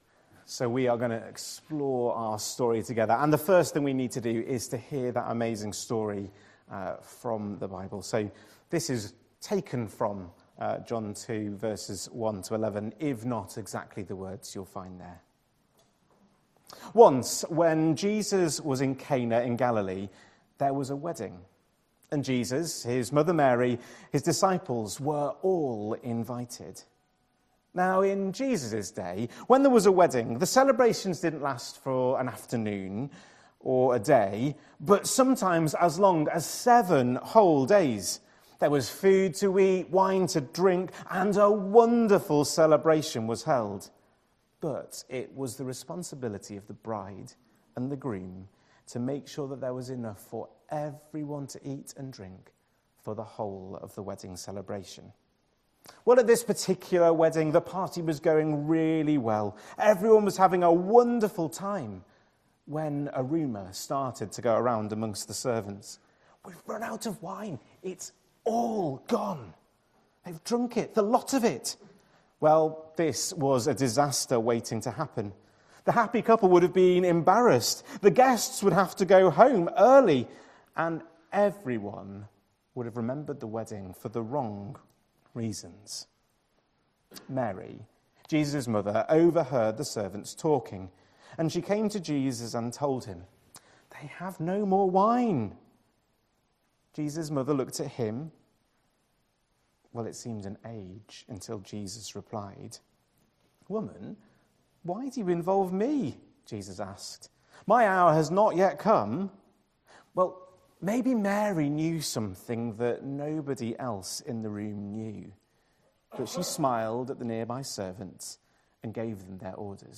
1st February 2026 Sunday Reading and Talk - St Luke's